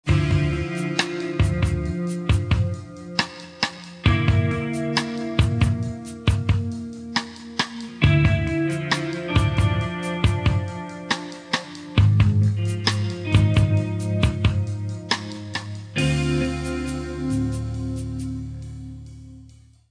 Clean 2 (156 kb, 19 sec)